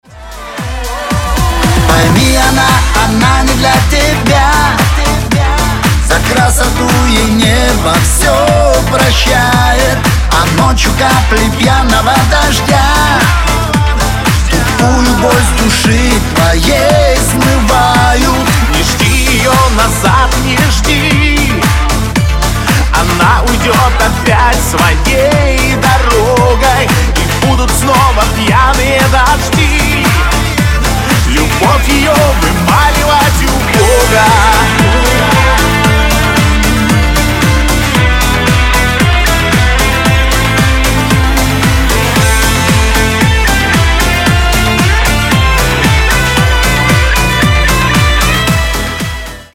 • Качество: 320, Stereo
мужской вокал
dance